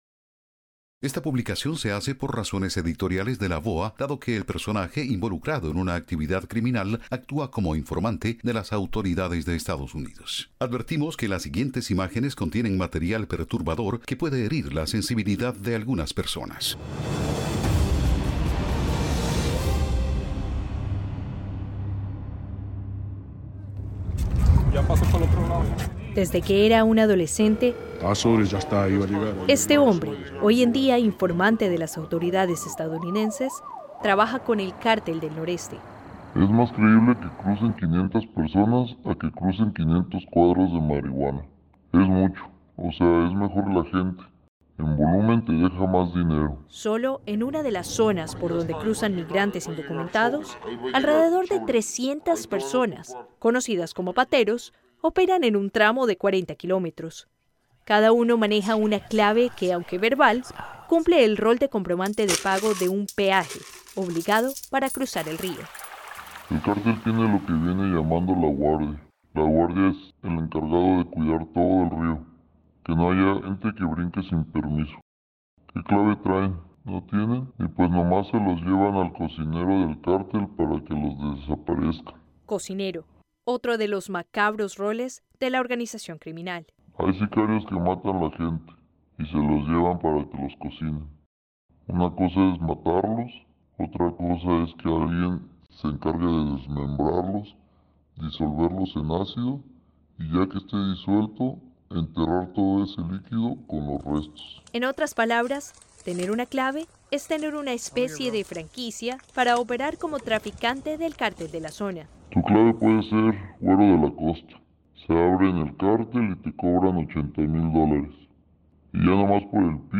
Concluimos este Especial que incluye una entrevista a un miembro de un cártel mexicano, sin revelar su identidad por razones editoriales de la VOA, actúa como informante de las autoridades de EEUU y revela el “modus operandi” del tráfico de migrantes.